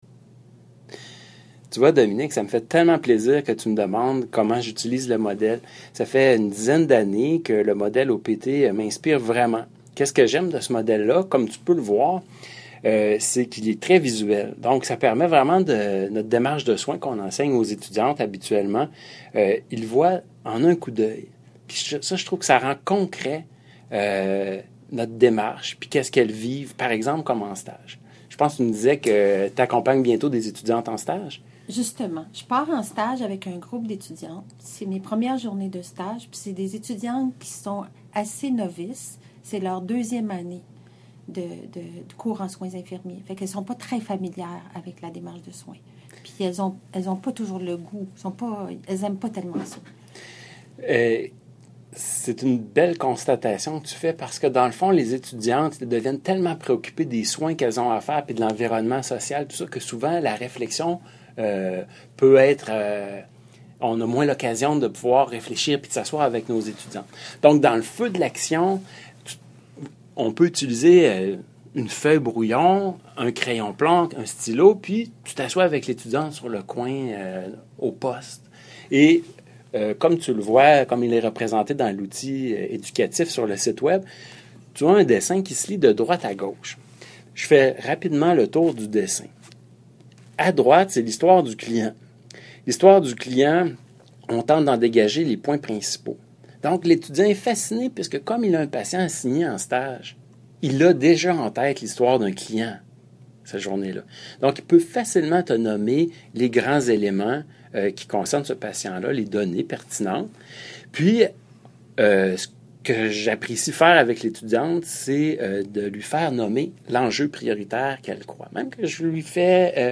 Cet outil éducatif comporte une capsule audio qui fait part d'un échange entre deux enseignants au sujet de l'utilisation du modèle OPT (Outcome, Present, Test) de Pesut et Herman (1998). Il est utilisé pour l'enseignement du raisonnement clinique et de la métacognition en stage ou en classe.